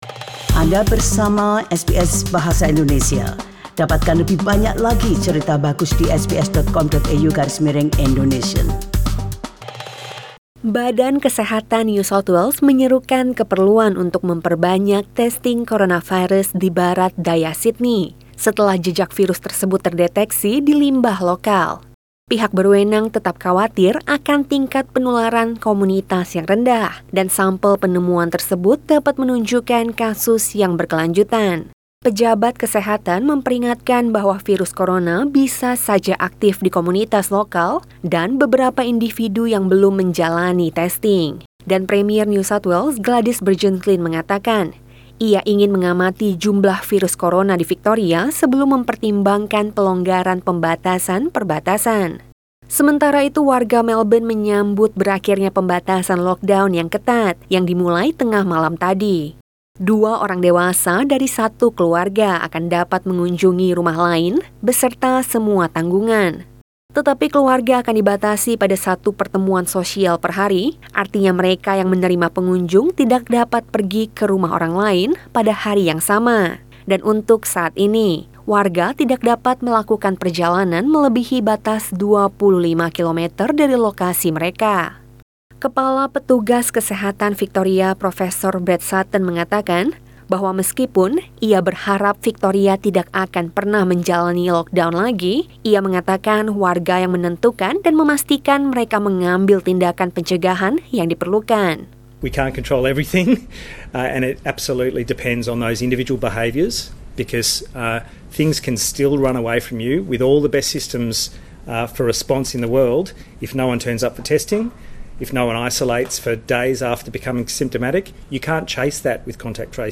SBS Radio News in Bahasa Indonesia - 28 October 2020